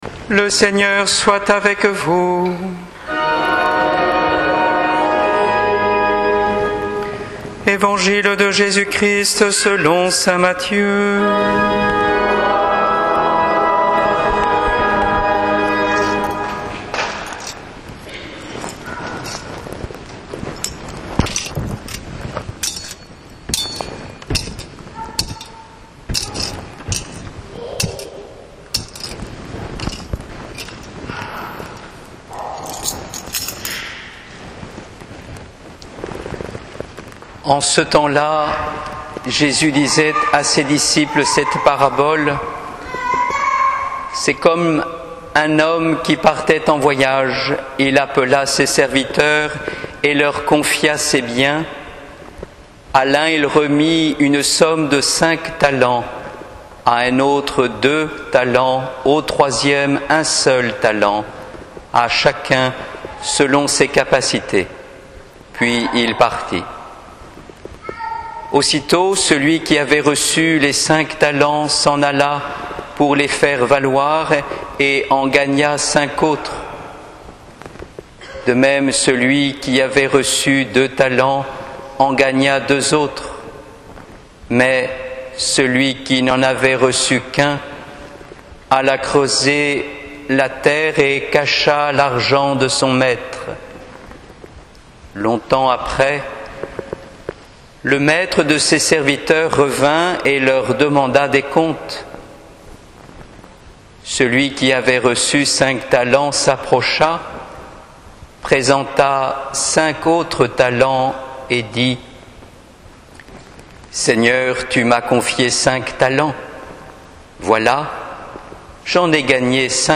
homelie33
homelie33.mp3